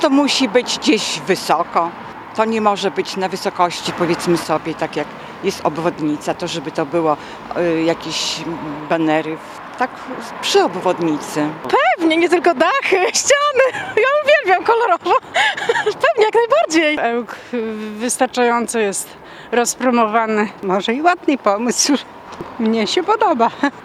Reporter Radia 5 spytał ełczan, co sądzą o tych pomysłach promocji miasta.